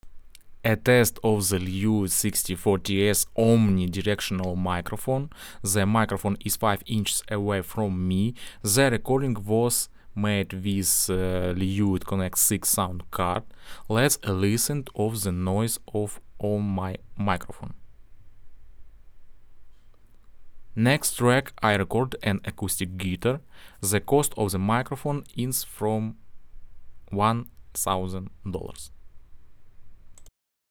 The sound of the Lewitt LCT 640 TS is open, detailed and surprisingly accurate.
The recordings are unprocessed, at the same Gain level.
Lewitt LCT 640 TS – omnidirectional diaphragm: